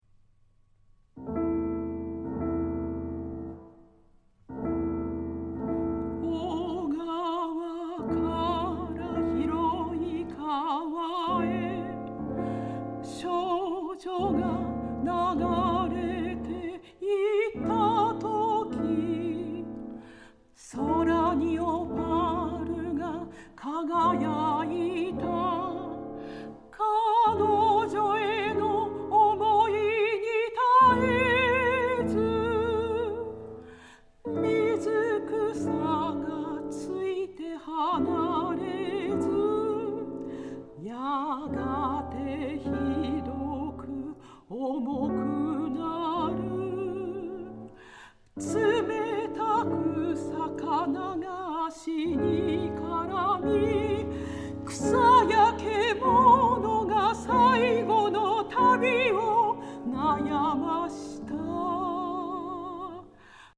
ヒリヒリとした緊張感と童謡のように優しく歌に包まれる感覚が同居していて◎！